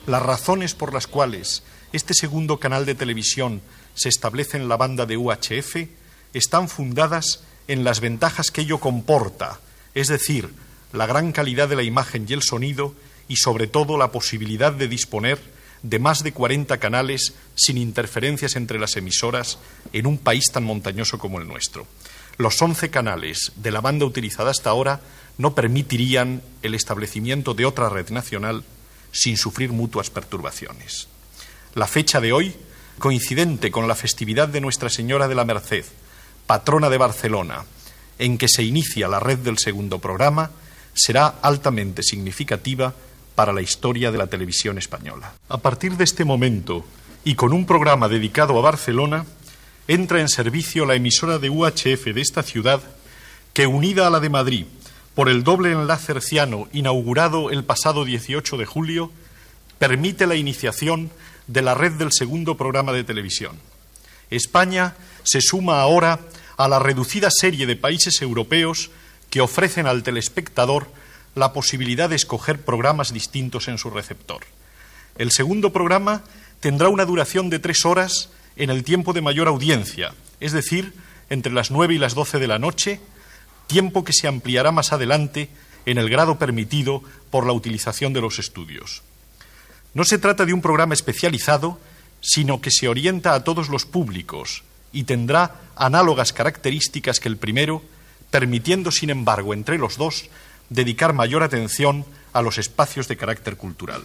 Jesús Aparicio Bernal, director de Radiodifusió, explica els avantatges de la banda d'UHF, en el dia de la inauguració de les transmissions del Segundo Canal de TVE a Barcelona
Informatiu